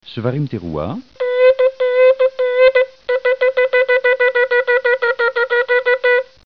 Le Choffar
Terouah", dont on sonne neuf fois au minimum, suivi d'une fusion de ces deux sons
Chevarim-Terouah.